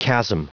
Prononciation du mot chasm en anglais (fichier audio)
Prononciation du mot : chasm